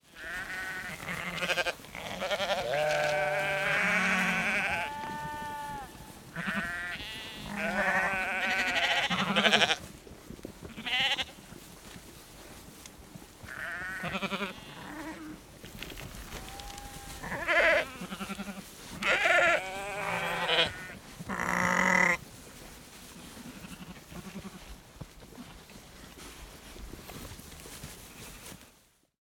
Звуки овец, коз
Вы услышите блеяние, жевание травы, звон колокольчиков и другие аутентичные звуки фермерской жизни.
Отара овец, пасущихся на траве и блеющих 2